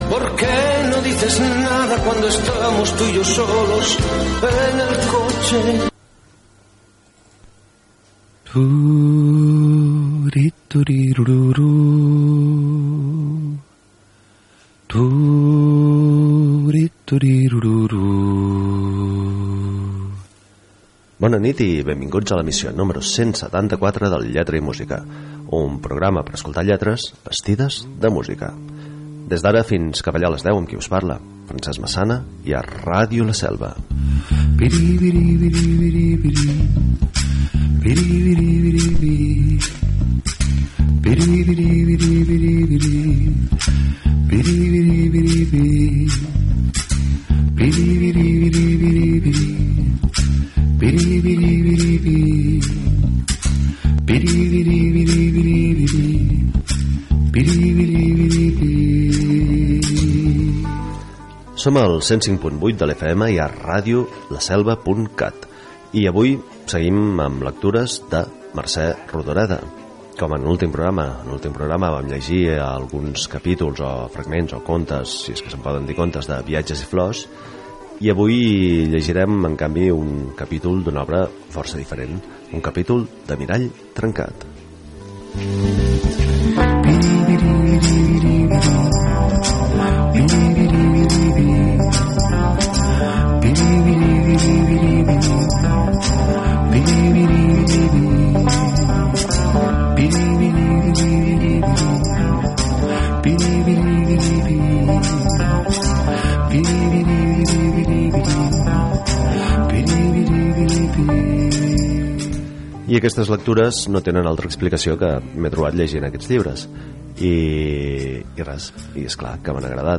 Un programa per escoltar lletres vestides de música. I per llegir textos nus. I per deixar-nos tapar amb músiques sense lletra.